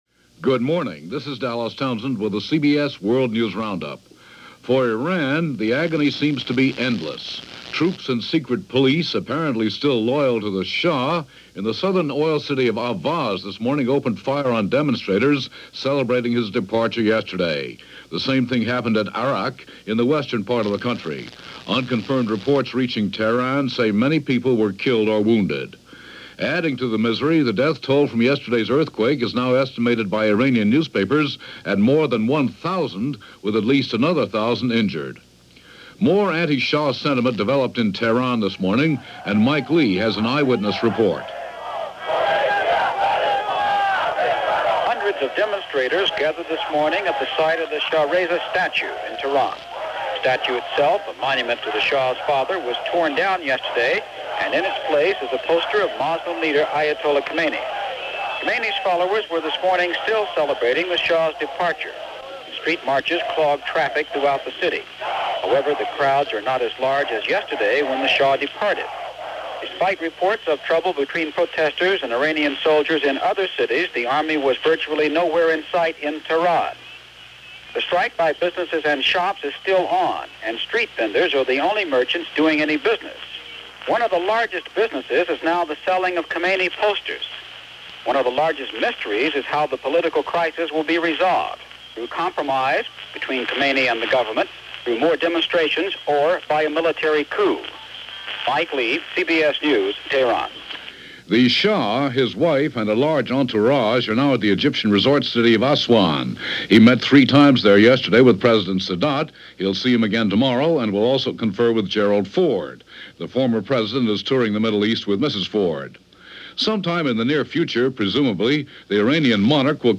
And that’s most of what went on this day – January 17, 1979, as reported by Dallas Townsend and The CBS World News Roundup.